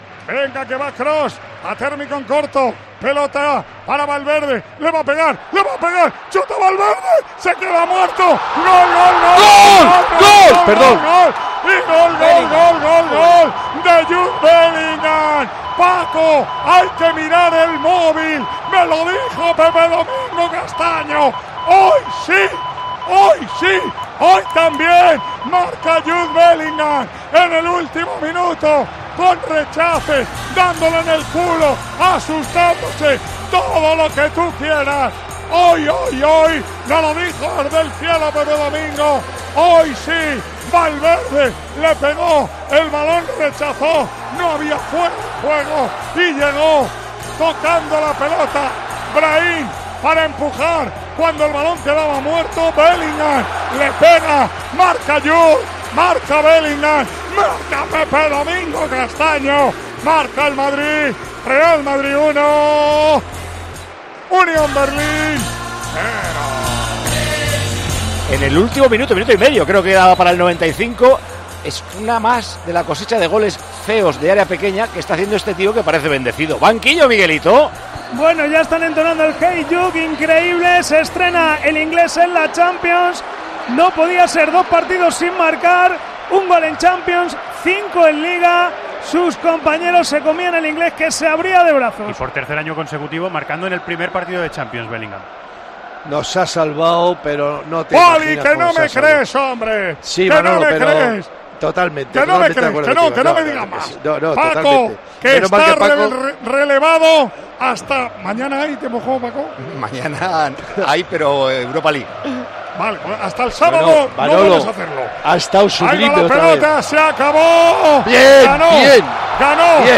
Así narró Manolo Lama los goles que llevaron al Real Madrid a conquistar la Decimoquinta